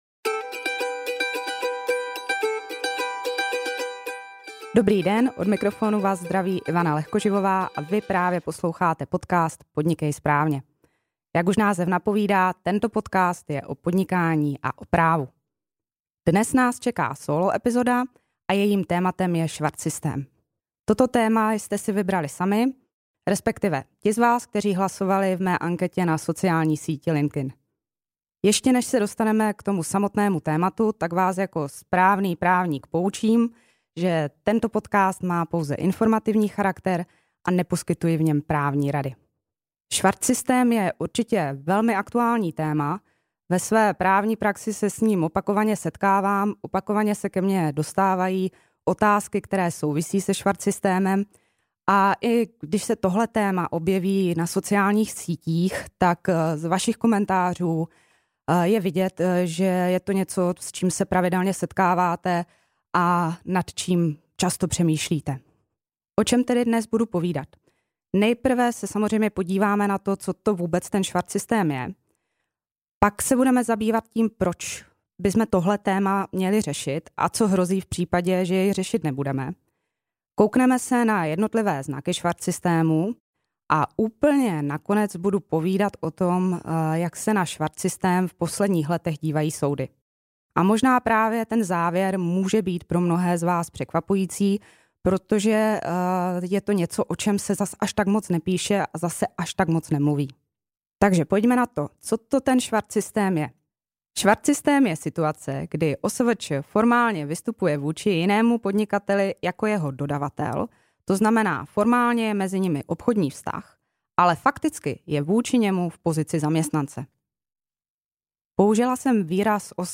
Headliner Embed Embed code See more options Share Facebook X Subscribe V této sólo epizodě se budu věnovat aktuálnímu tématu, se kterým se ve své právní praxi pravidelně setkávám, a tím je švarcsystém. Dozvíte se nejen, co to švarcsystém je a jaké jsou jeho jednotlivé znaky, ale i to, proč byste měli toto téma řešit a co hrozí, když ho řešit nebudete. Poslouchejte až do konce, dozvíte se, jak se na švarcsystém v posledních letech dívají soudy.